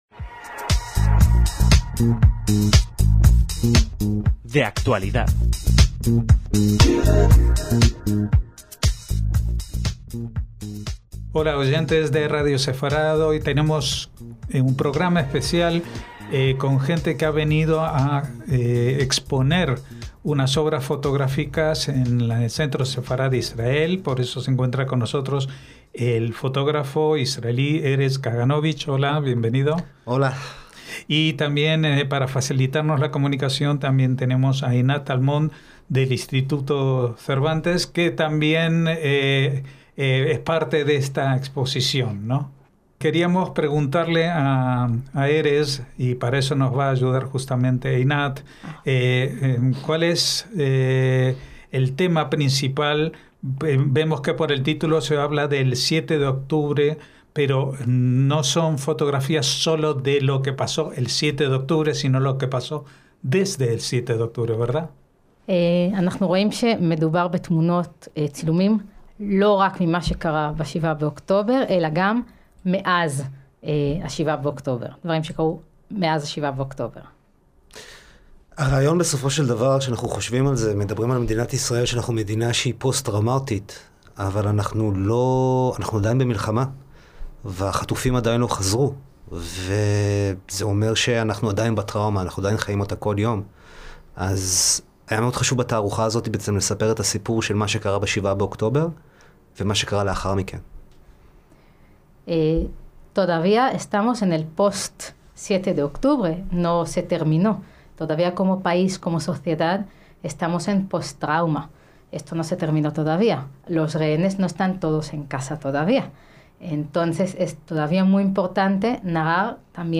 a quien entrevistamos en hebreo